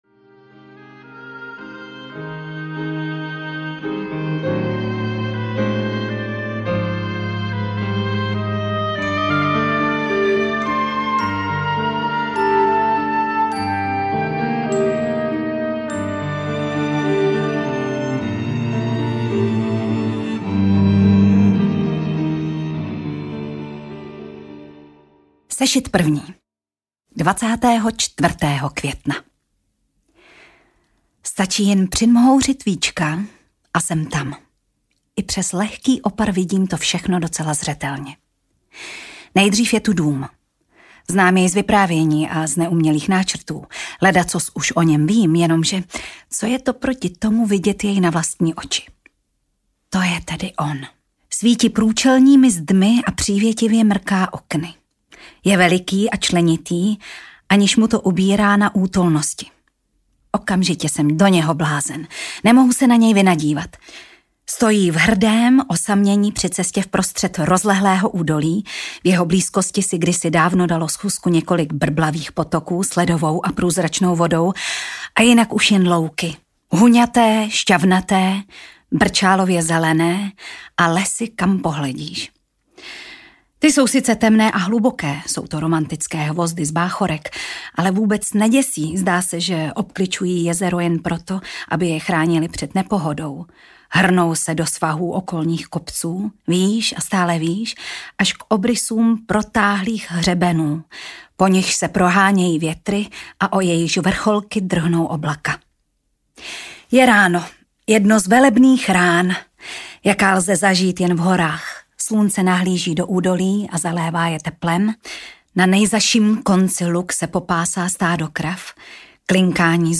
Když v ráji pršelo audiokniha
Ukázka z knihy
Tatiana Dyková ve své interpretaci tohoto proslulého románu nesmírně citlivě a účinně zdůraznila něhu, humor a láskyplnou sílu milostné dvojice.
• InterpretTatiana Dyková